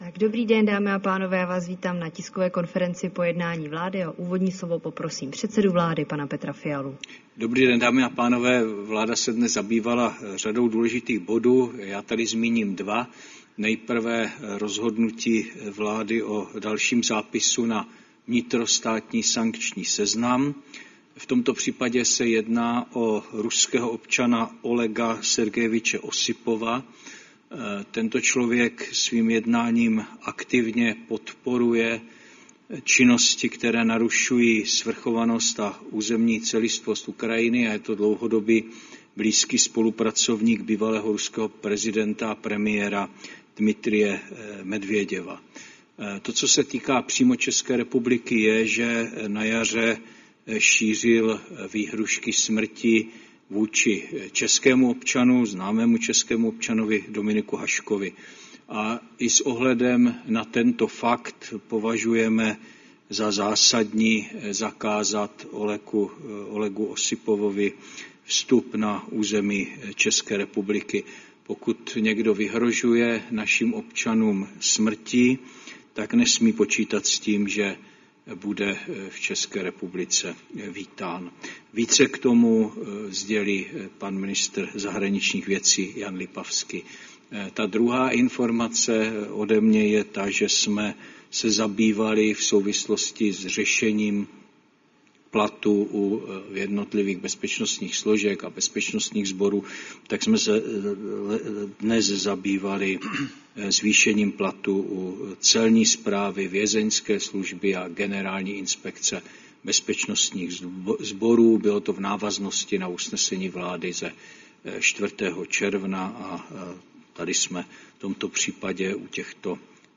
Tisková konference po jednání vlády, 2. července 2025